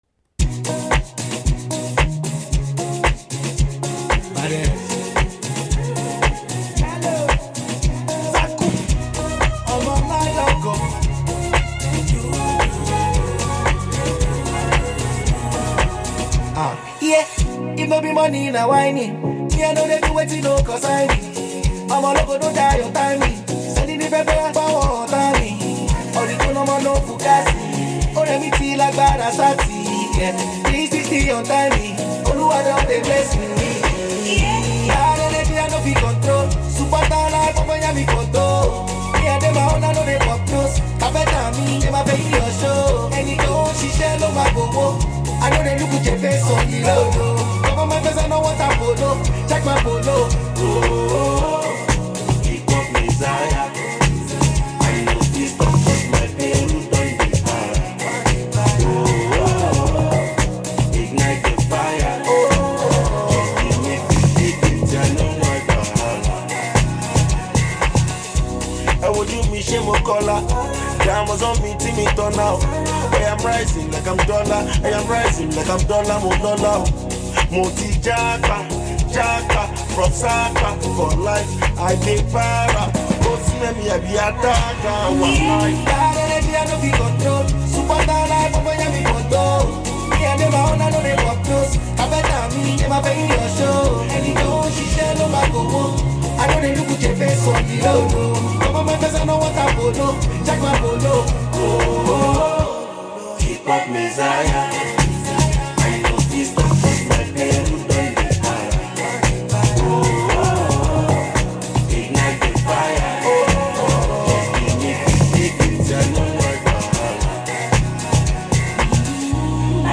It’s bold. It’s loud.
Nigerian hip hop